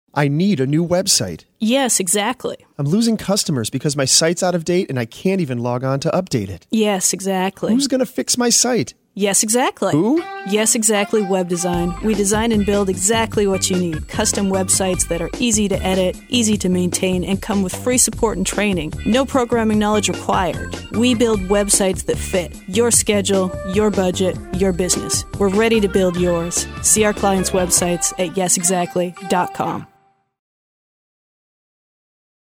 The guys at the studio were perfectionists when it came to finding just the right sound effect for a can opener slicing… or a wine glass clinking. I am told that our backing tracking contains both a Hammond organ and a triangle. Pretty funky.